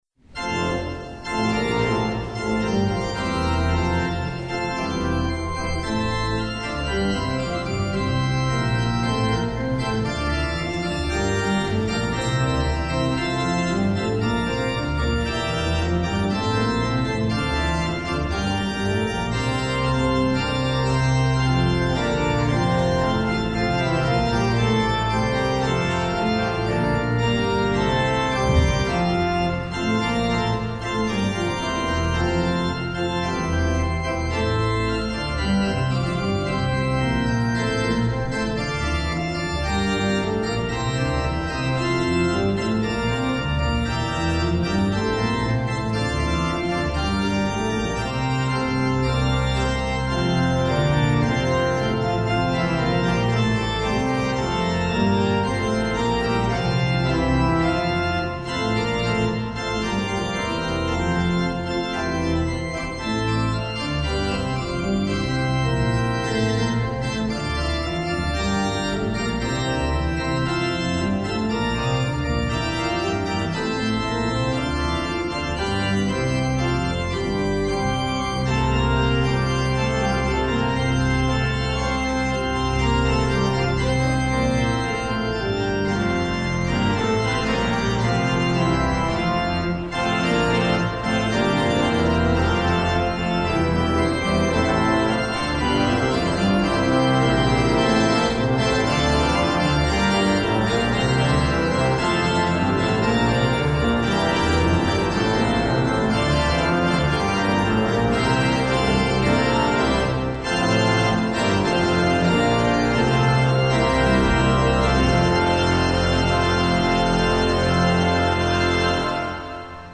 Hear the Bible Study from St. Paul's Lutheran Church in Des Peres, MO, from March 22, 2026.